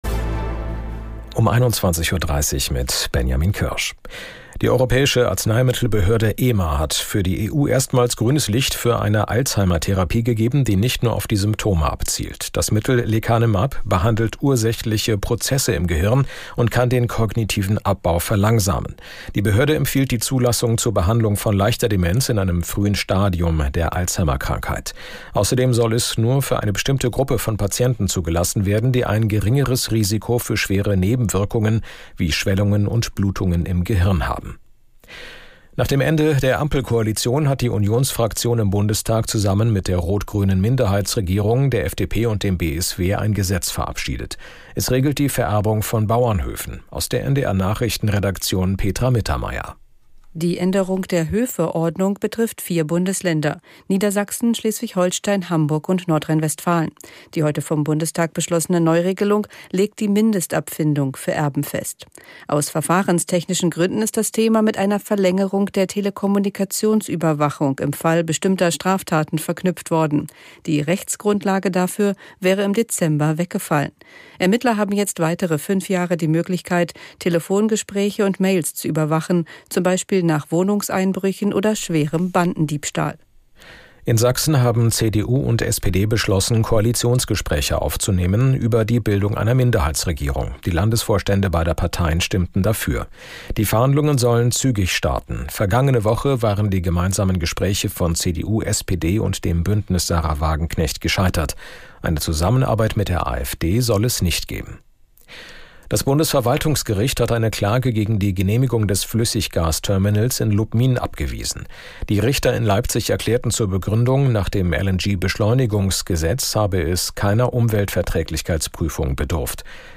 1 Nachrichten 3:17